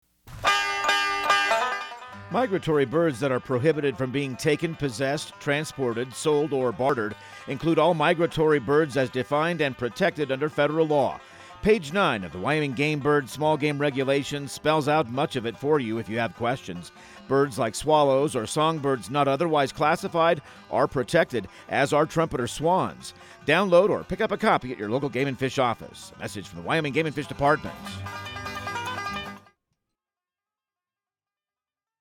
Outdoor Tip/PSA